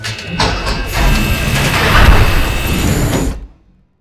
CloseAirlock.wav